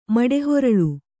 ♪ maḍe horaḷu